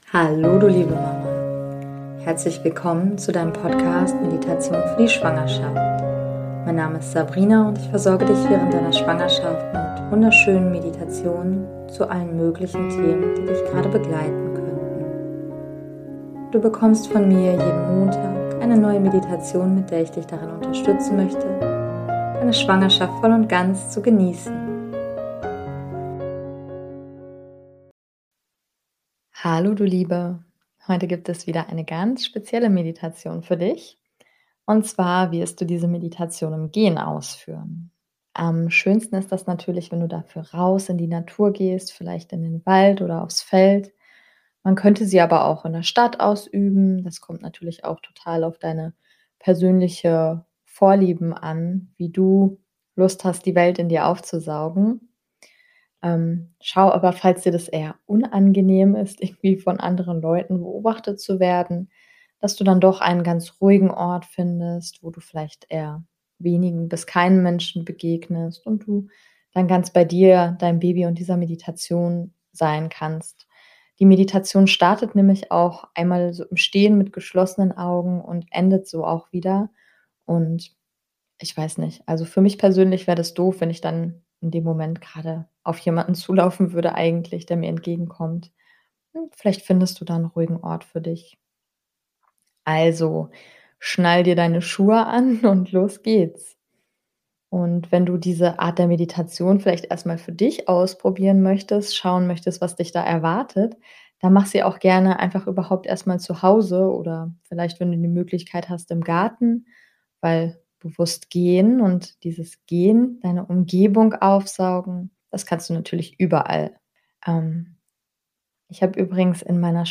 Heute gibt es wieder eine ganz spezielle Meditation für dich. Und zwar wirst du diese Meditation im Gehen ausführen.